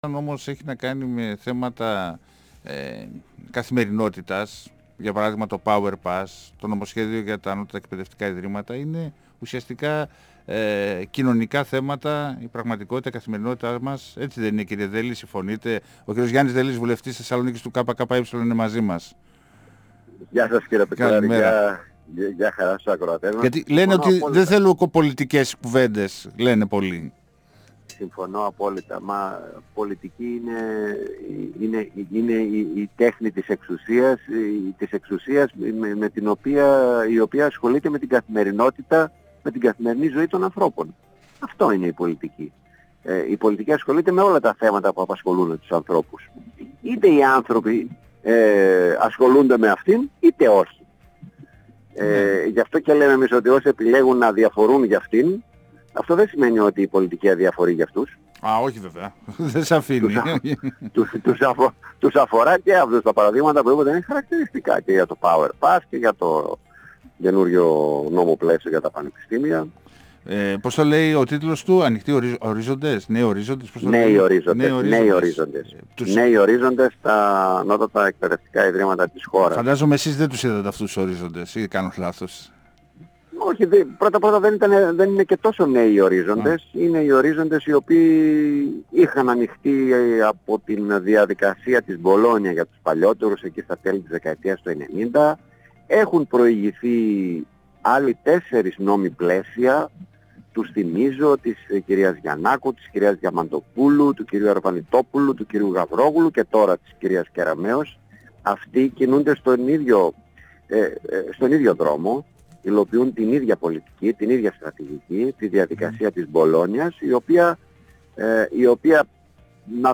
Κριτική στο νέο νόμο πλαίσιο για τα ΑΕΙ άσκησε ο βουλευτής Θεσσαλονίκης Γιάννης Δελής. 102FM Συνεντεύξεις ΕΡΤ3